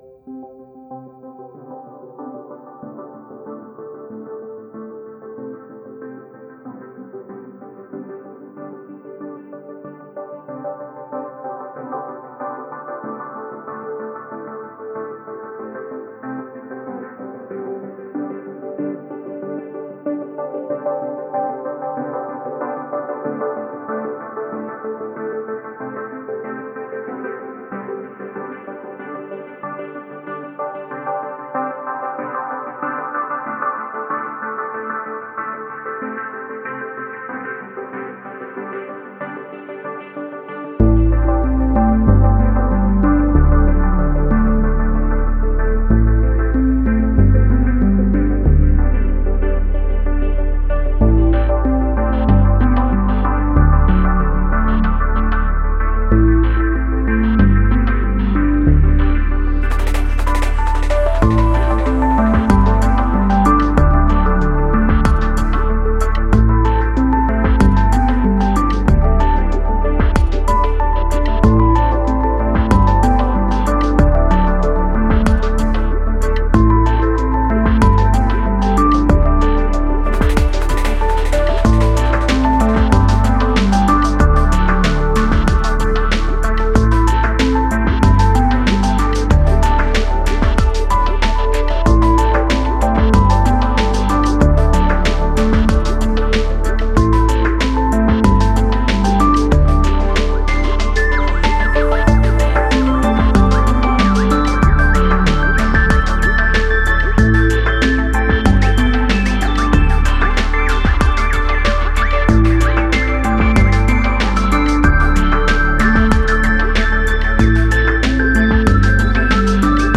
Genre: Ambient, IDM, Chillout.